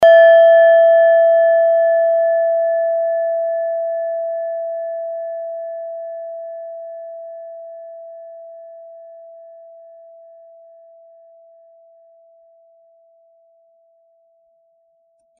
Planetentonschale: Hopiton
Hörprobe der Klangschale
Die Frequenz des Hopitons liegt bei 164,8 Hz und dessen tieferen und höheren Oktaven. In unserer Tonleiter liegt sie beim "E".
klangschale-nepal-15.mp3